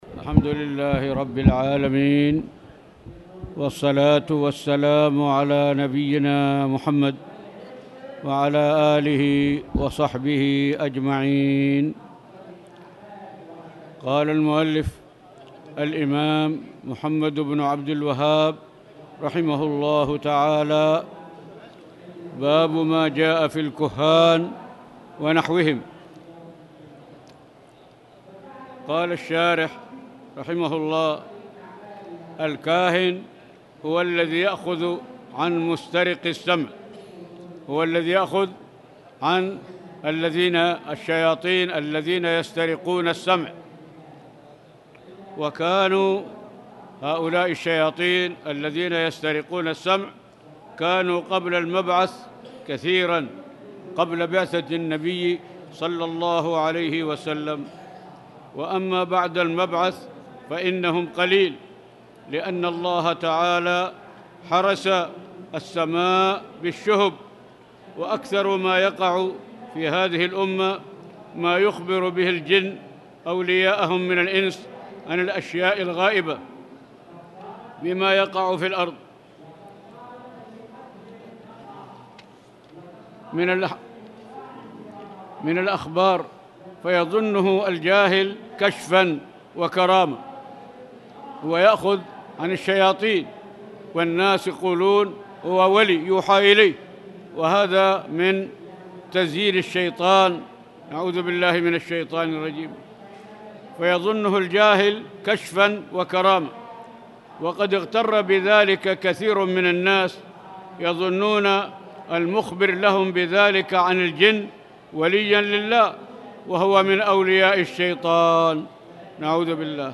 تاريخ النشر ١ محرم ١٤٣٨ هـ المكان: المسجد الحرام الشيخ